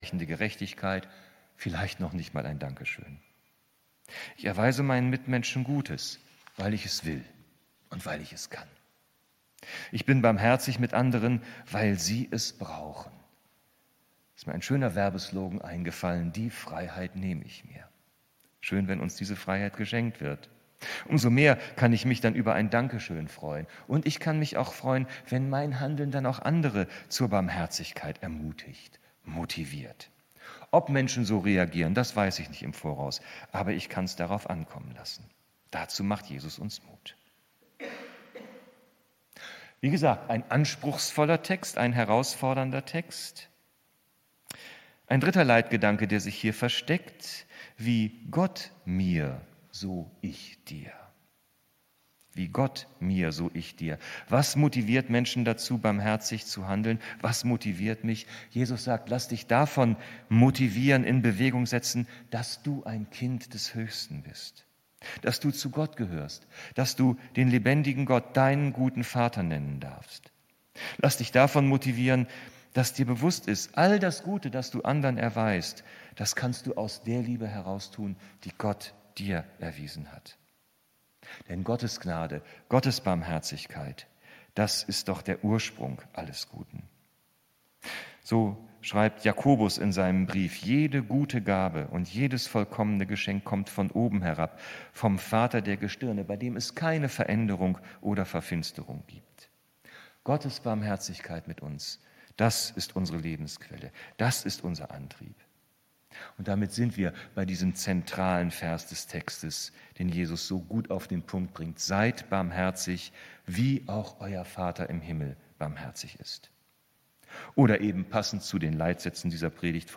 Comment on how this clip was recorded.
Gottesdienst am 23. Juni 2024 aus der Christuskirche Altona on 23-Jun-24-10:03:14 Videos und Livestreams aus der Christuskirche Hamburg Altona (Baptisten)